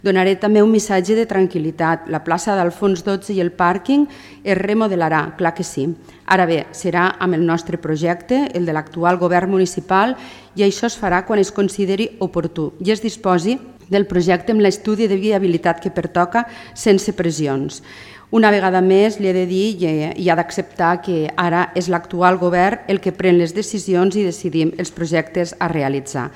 Escoltem a Mar Lleixà, portaveu d’ERC i a Francesc Vallespí regidor de Movem-PSC